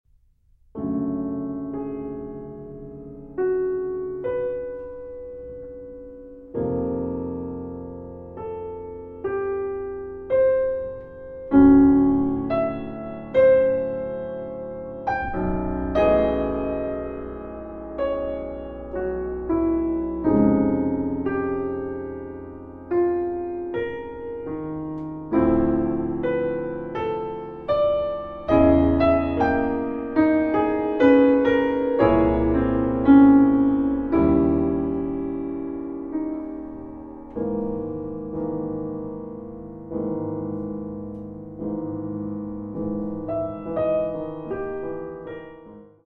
Reflective (4:42)